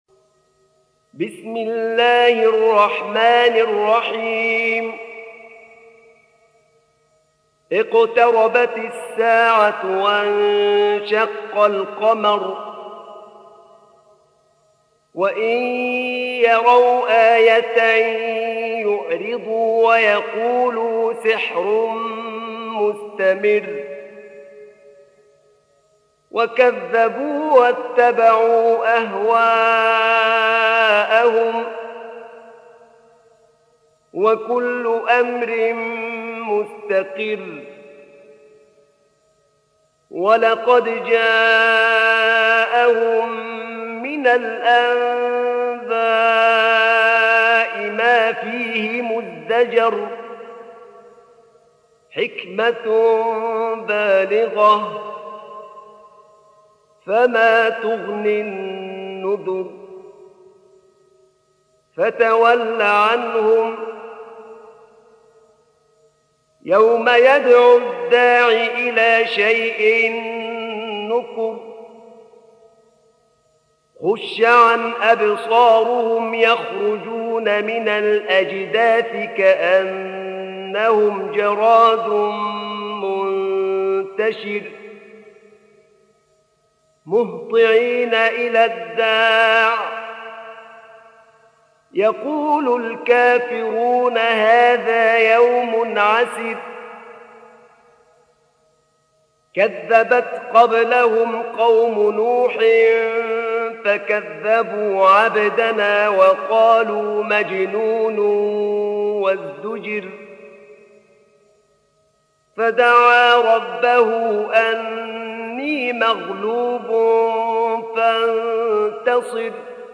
سورة القمر | القارئ أحمد نعينع